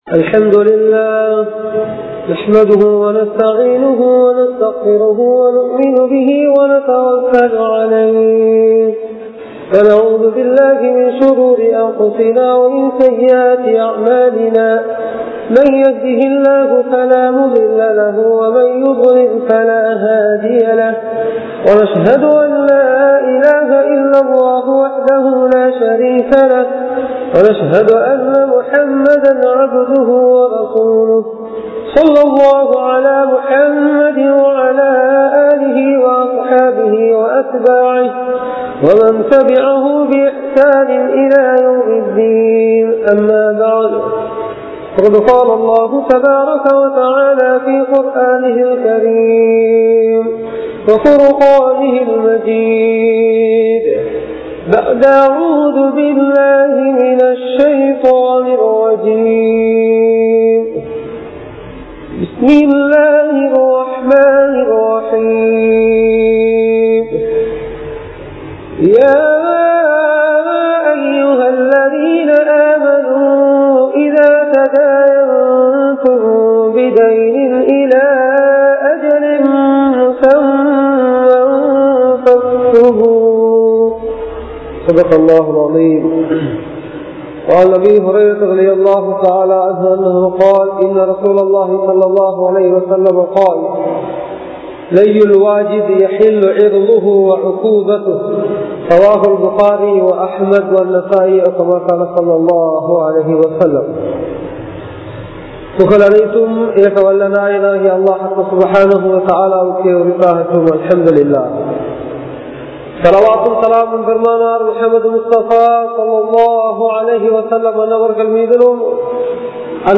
Islamiya Paarvaiel Kadan (இஸ்லாமிய பார்வையில் கடன்) | Audio Bayans | All Ceylon Muslim Youth Community | Addalaichenai